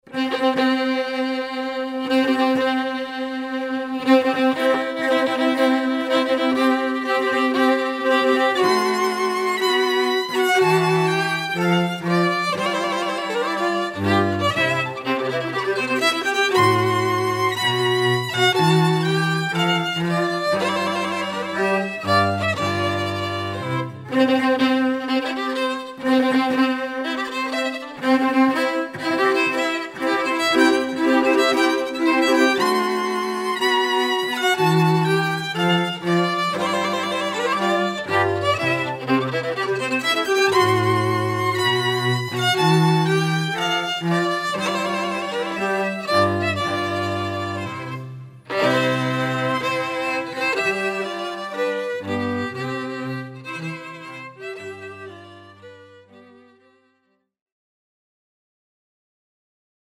(Violin, Viola, Cello)